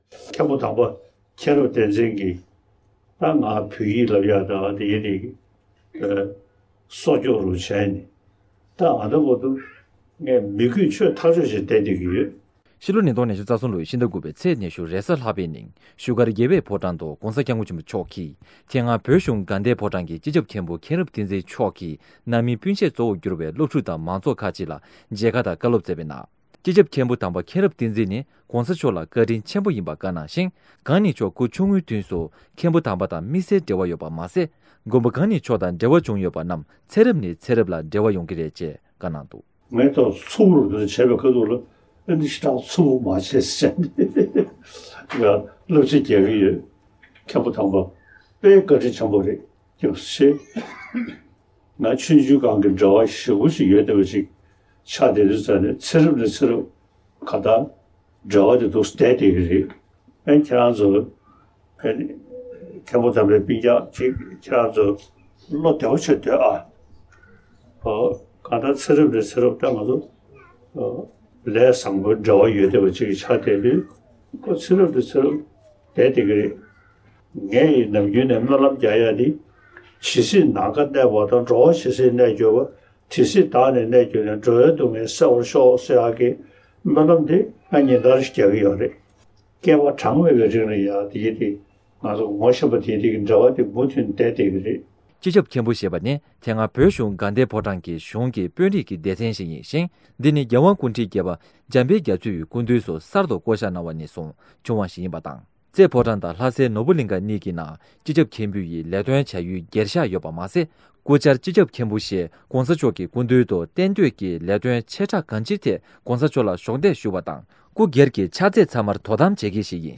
སྤྱི་ནོར་༧གོང་ས་མཆོག་གིས་མང་ཚོགས་ལ་མཇལ་ཁའི་སྐབས་བཀའ་སློབ་སྩལ་བཞིན་པ།
སྒྲ་ལྡན་གསར་འགྱུར།